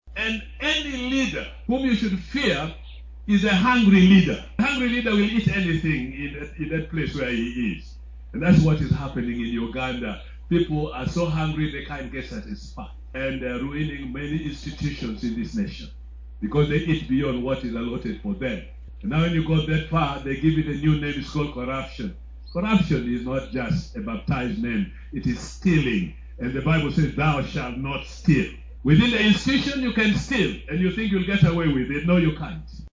In a compelling address at Muni University, the Archbishop Emeritus of the Church of Uganda and Chancellor of the institution, Henry Luke Orombi, made a passionate appeal to Uganda's leaders to combat corruption.